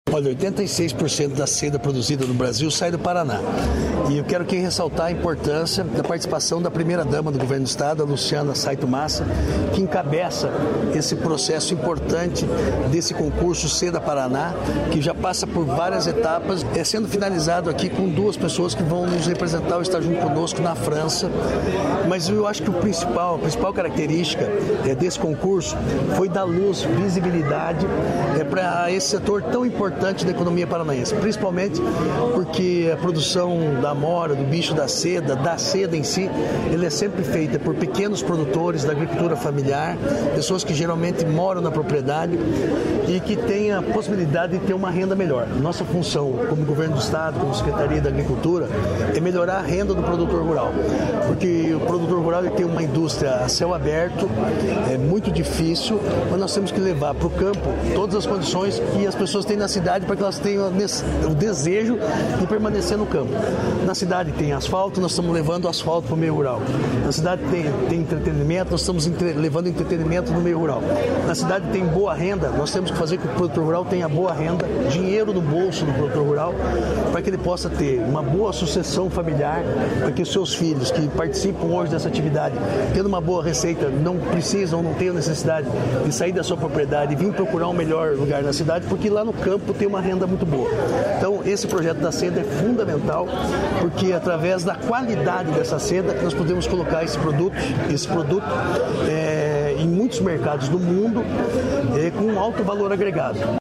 Sonora do secretário estadual da Agricultura e do Abastecimento, Marcio Nunes, sobre a premiação das produtoras de seda